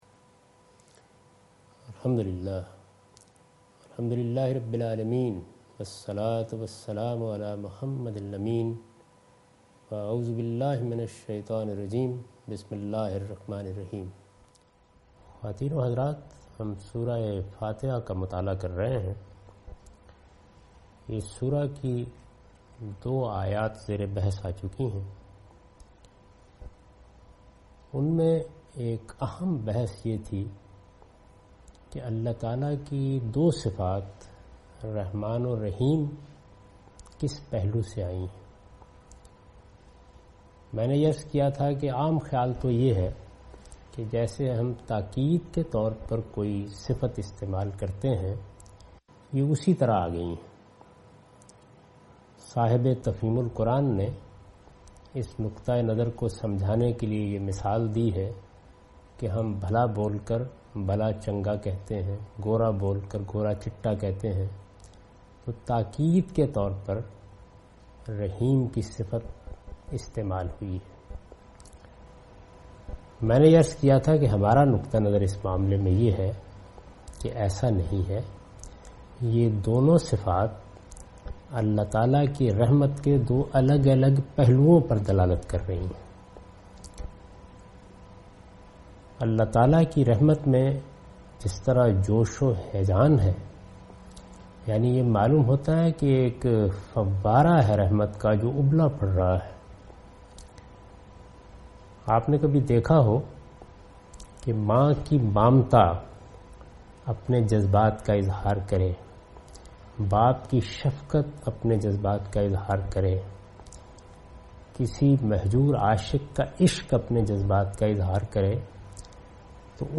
A comprehensive course on Quran, wherein Javed Ahmad Ghamidi teaches his tafseer ‘Al Bayan’.
In this sitting he teaches first surah of 'Al Fatiha'. (Lecture recorded on 7th March 2013).